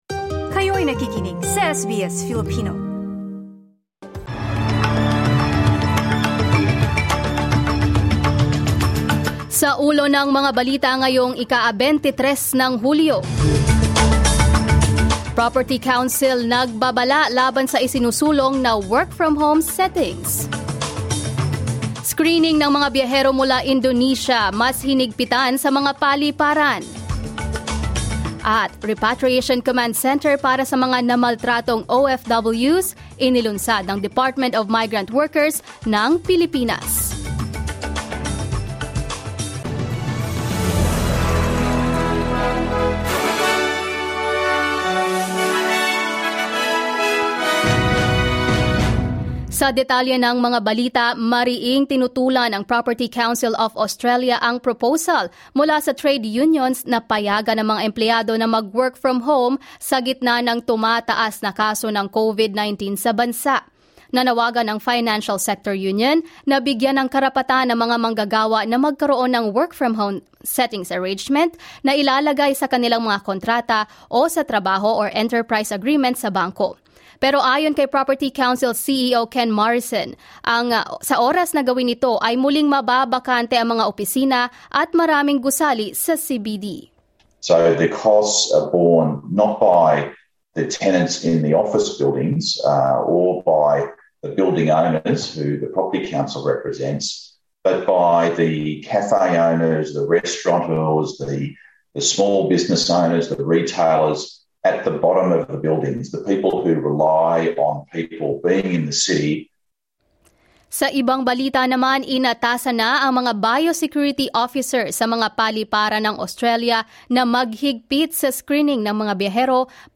SBS News in Filipino, Saturday 23 July